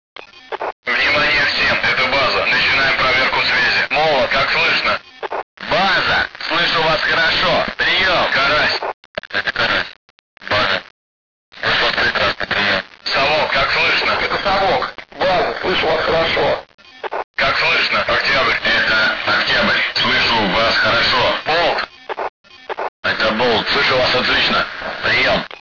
radiochat.wav